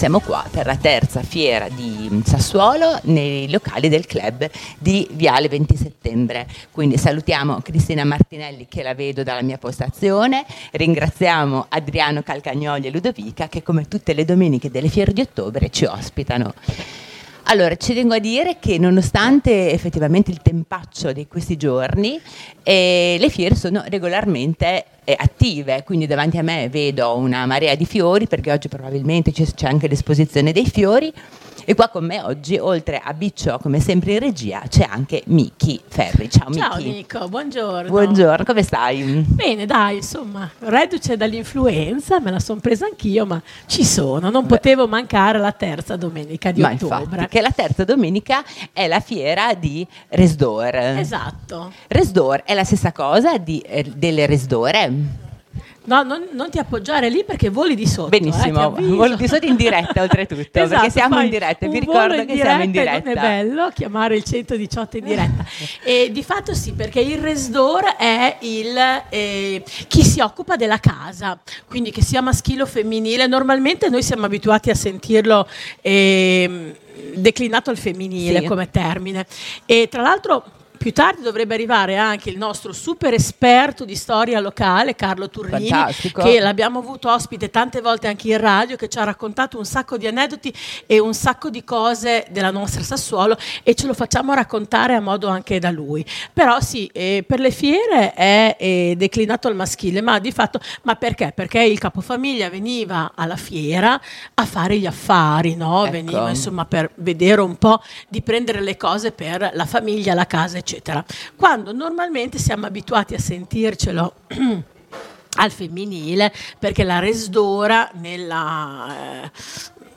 Le dirette di Linea Radio al Clhub di viale XX Settembre a Sassuolo